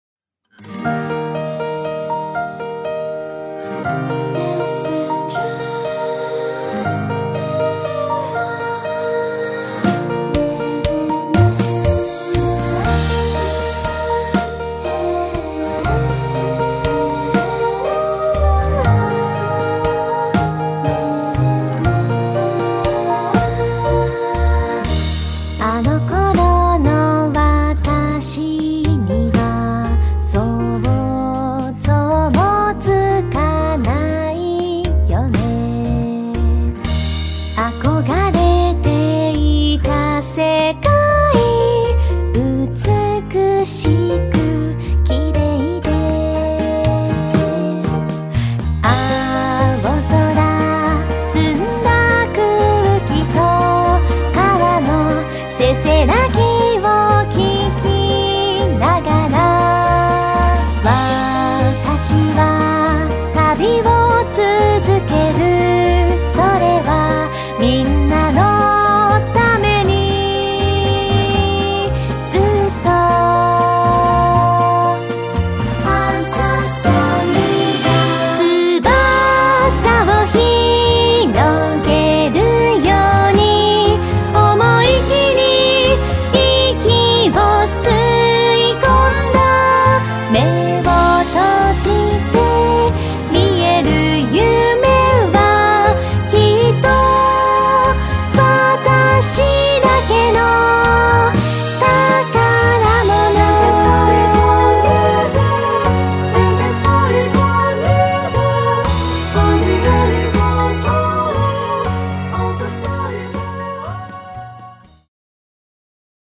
6曲入り オリジナルボーカルアルバム
多重録音コーラスと民族調RPGサウンド